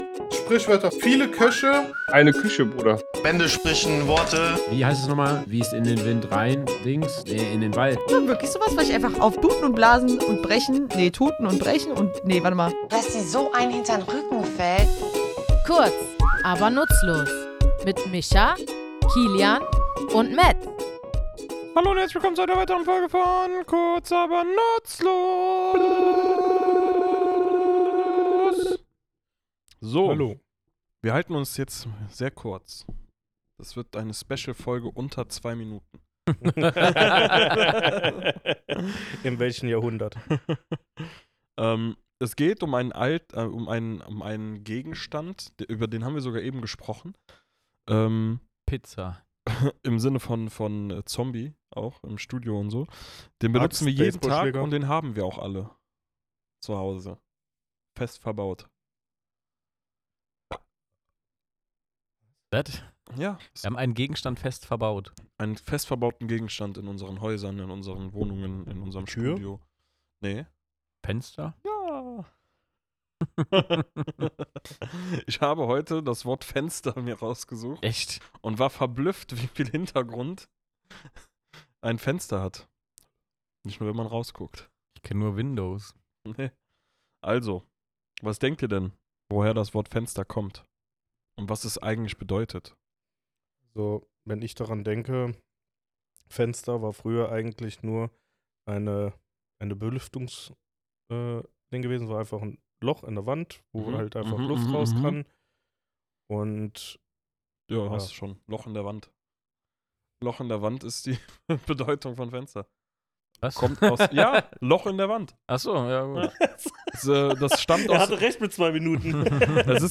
Ein alltäglicher Begriff – doch woher kommt er eigentlich, und was hat er mit Wind, Licht und lateinischer Sprachgeschichte zu tun? Wir, drei tätowierende Sprachliebhaber, schauen in unserem Tattoostudio genauer durchs Fenster der deutschen Sprache.